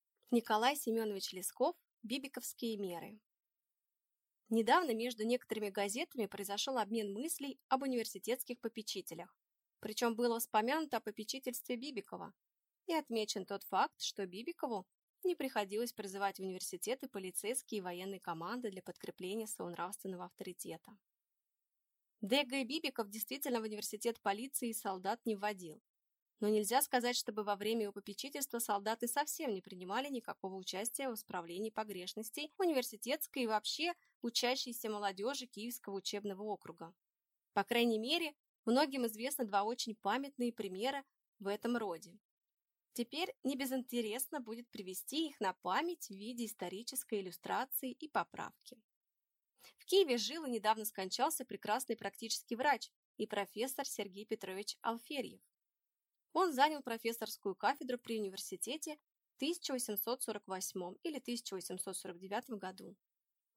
Аудиокнига Бибиковские «меры» | Библиотека аудиокниг